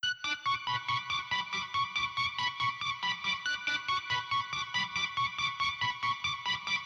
Distorted Arp.wav